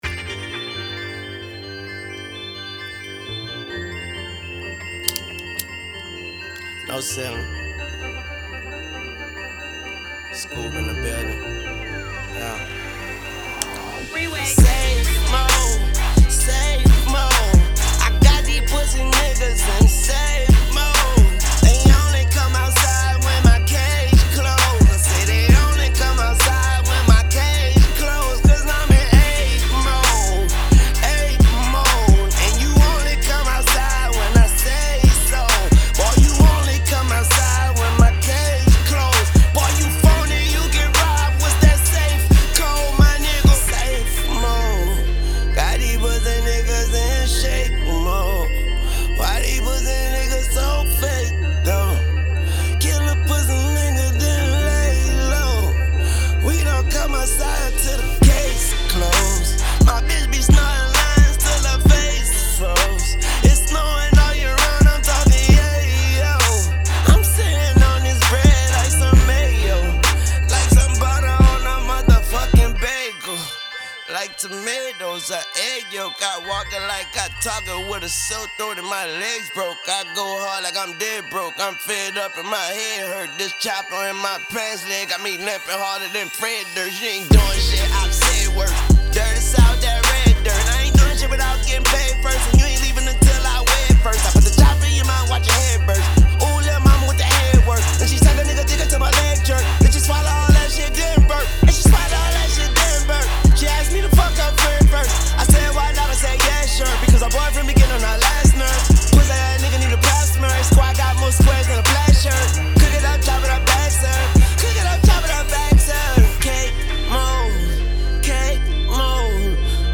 hiphop
hot freestyle